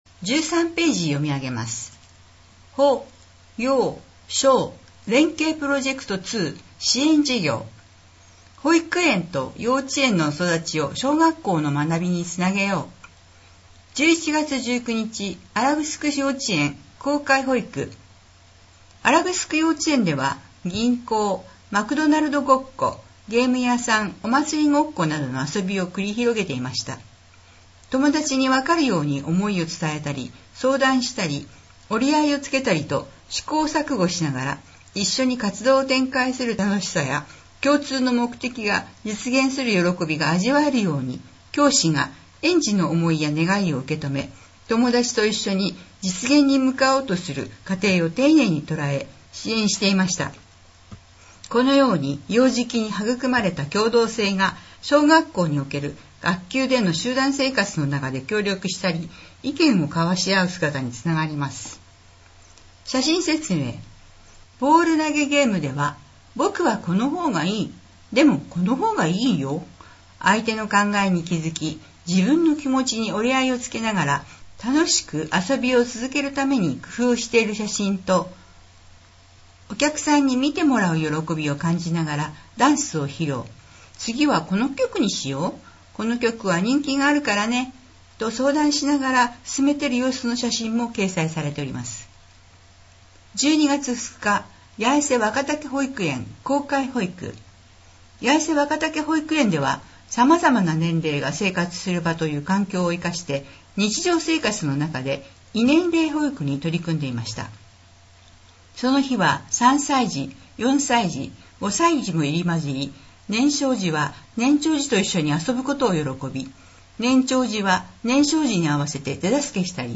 この音声は「音訳サークルやえせ」の皆さんのご協力で作成しています。